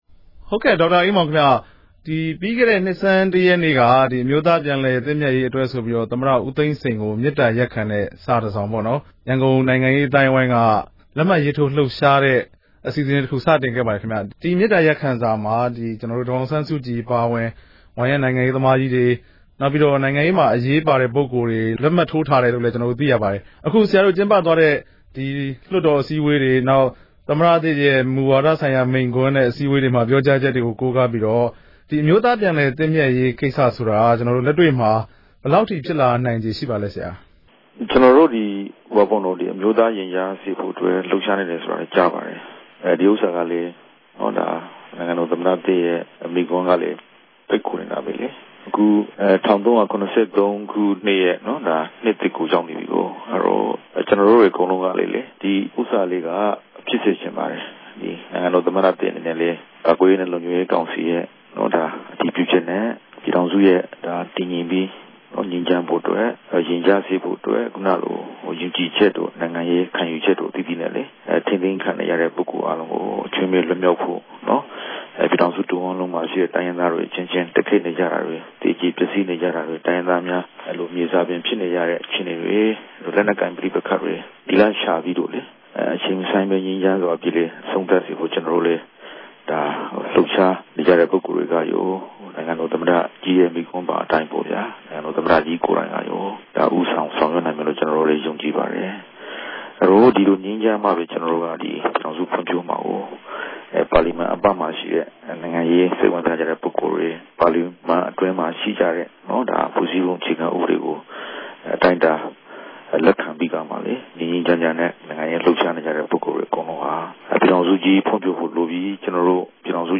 အမျိုးသားလွှတ်တော် အမတ်တဦးလည်းဖြစ်၊ ရခိုင် တိုင်းရင်းသားများ တိုးတက်ရေးပါတီ RNDP ရဲ့ ဥက္ကဋ္ဌ လည်းဖြစ်တဲ့ ဒေါက်တာအေးမောင်က အခုလို ပြောလိုက်တာပါ။
ဆက်သွယ်မေးမြန်းချက်။